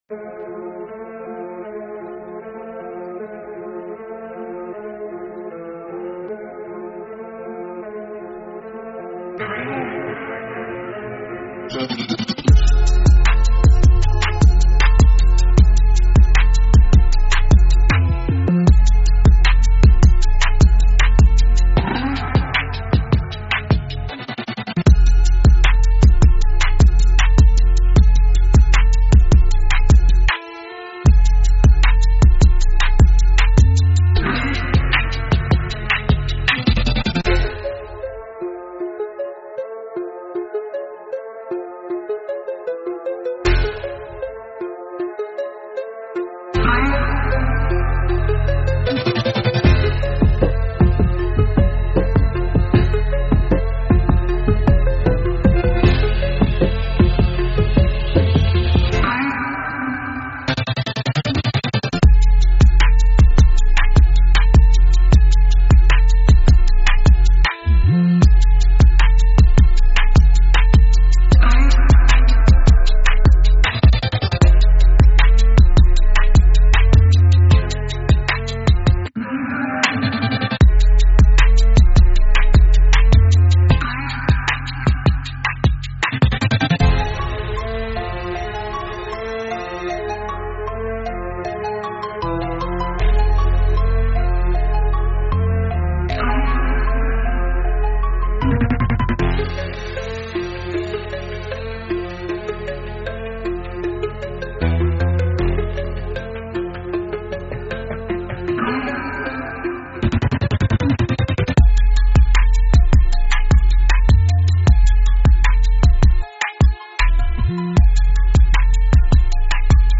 Instrumental - Real Liberty Media DOT xyz - Grimnir Radio